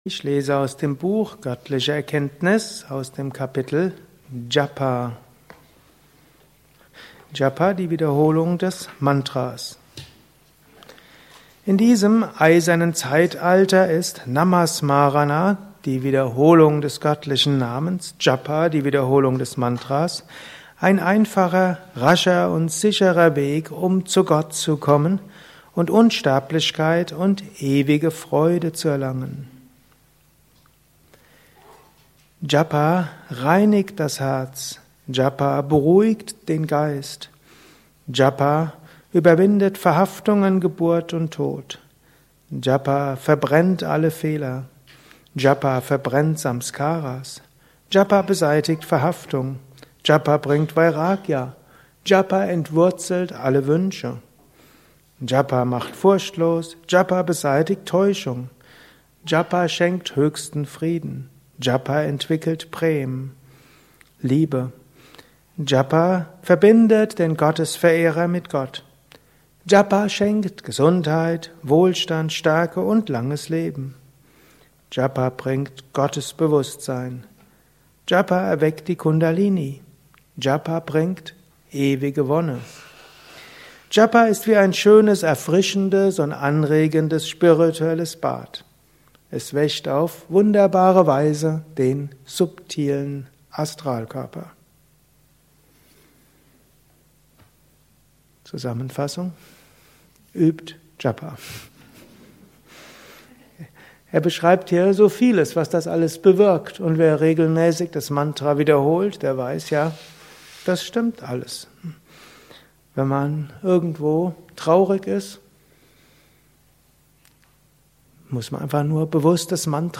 Vortrag